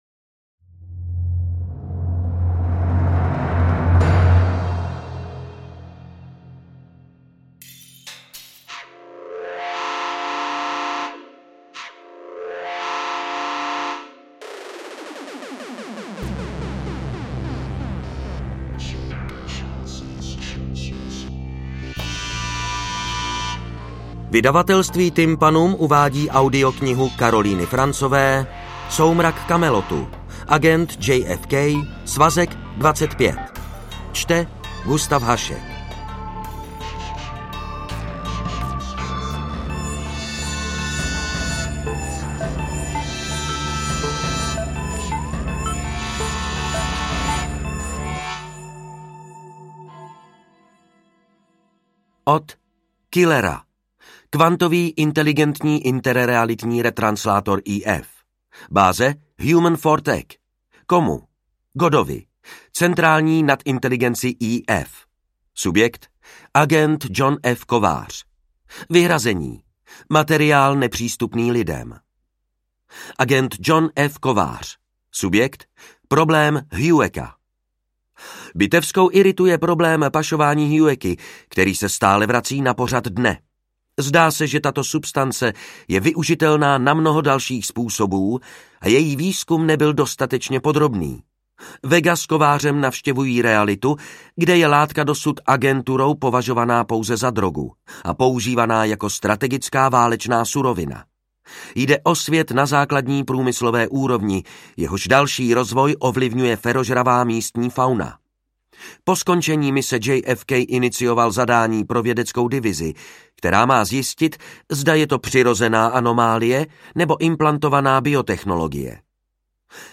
AudioKniha ke stažení, 24 x mp3, délka 8 hod. 16 min., velikost 455,2 MB, česky